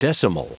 Prononciation du mot decimal en anglais (fichier audio)
Prononciation du mot : decimal